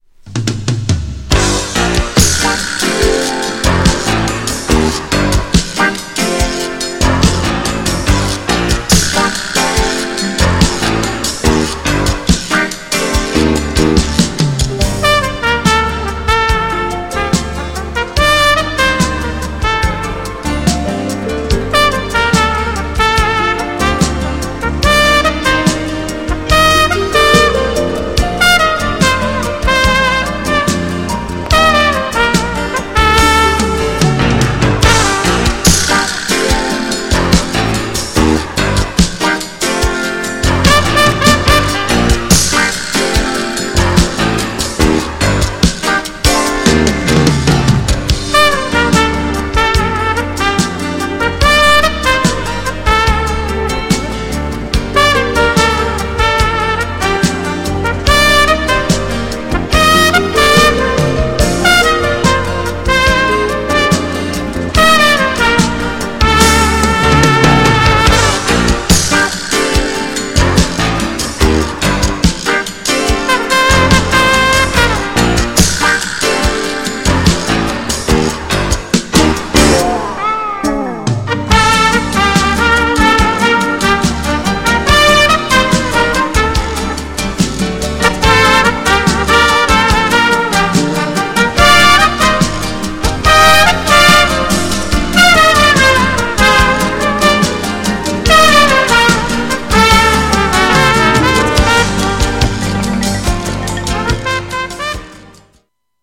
GENRE Dance Classic
BPM 131〜135BPM
GROOVY
INSTRUMENTAL # JAZZY
トランペット